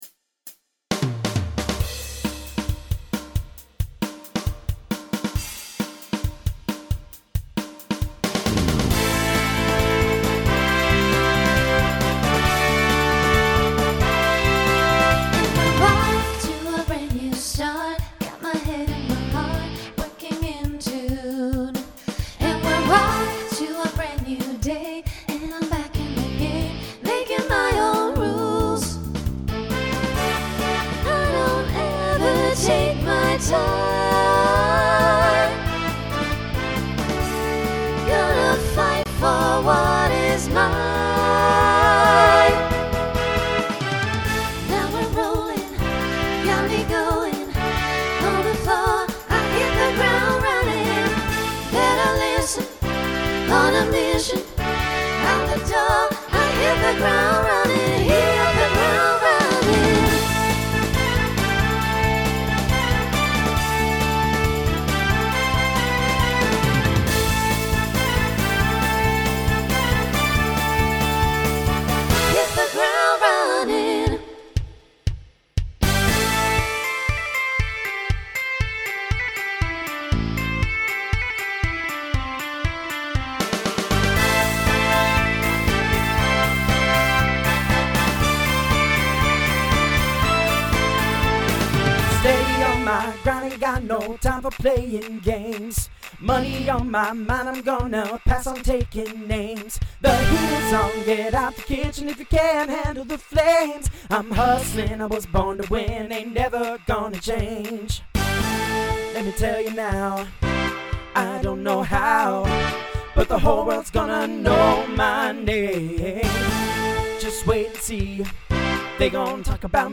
SSA/TTB
Voicing Mixed Instrumental combo Genre Rock